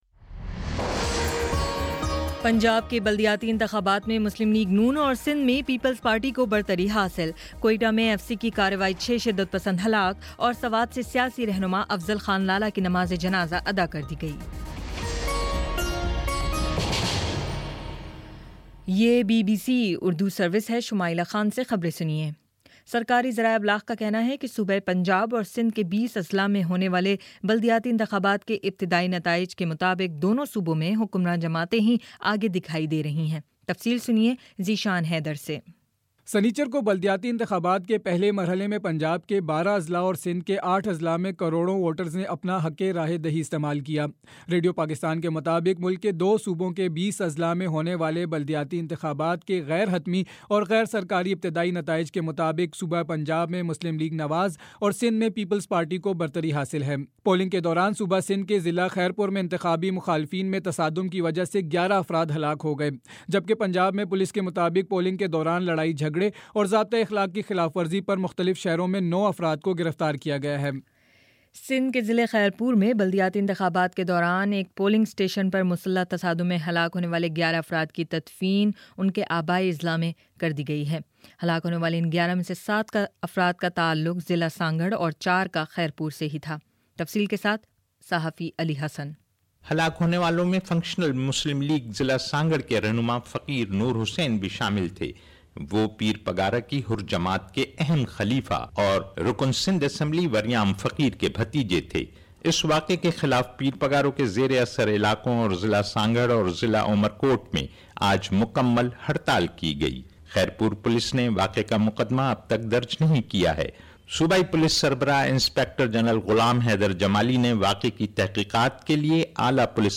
نومبر 01 : شام پانچ بجے کا نیوز بُلیٹن